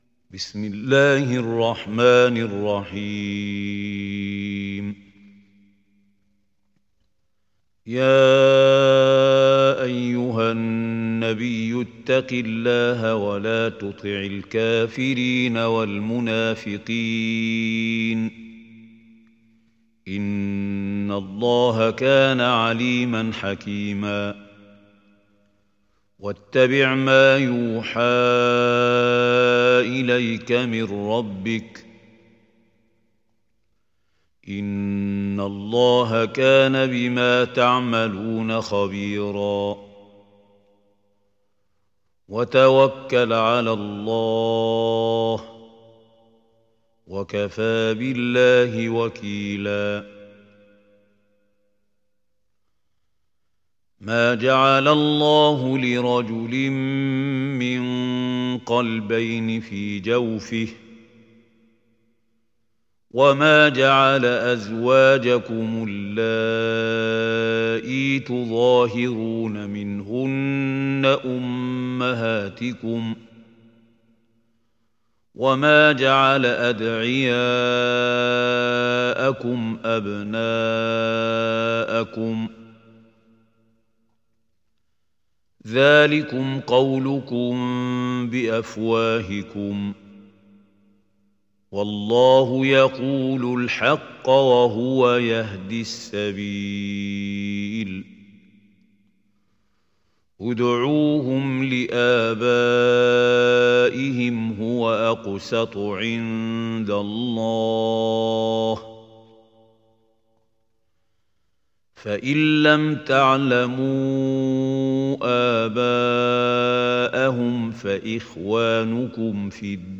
دانلود سوره الأحزاب mp3 محمود خليل الحصري روایت حفص از عاصم, قرآن را دانلود کنید و گوش کن mp3 ، لینک مستقیم کامل